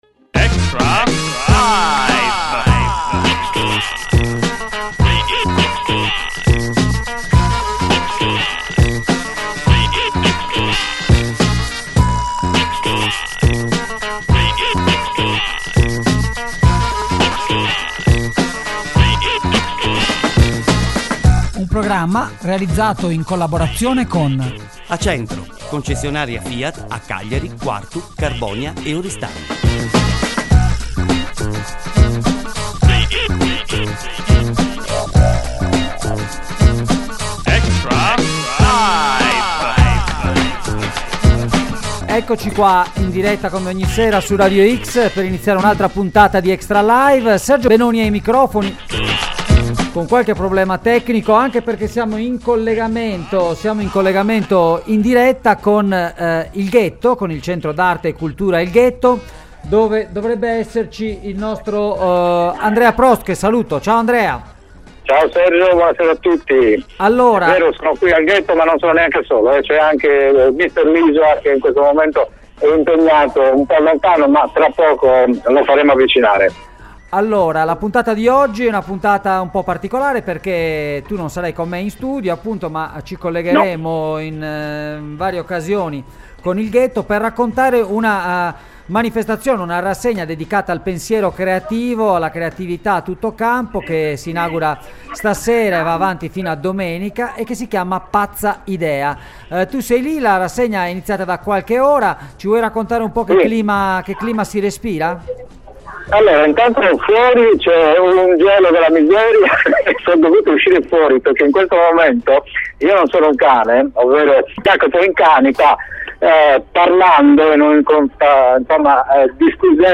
Puntata speciale di Extralive dedicata all’arte, al cinema, alla creatività e ai nuovi media: in studio
in collegamento dal Ghetto i nostri due inviati speciali